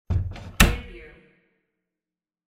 Closet door close sound effect .wav #2
Description: The sound of a closet door being closed
Properties: 48.000 kHz 16-bit Stereo
Keywords: closet, door, close, closing, shut, shutting
closet-door-close-preview-2.mp3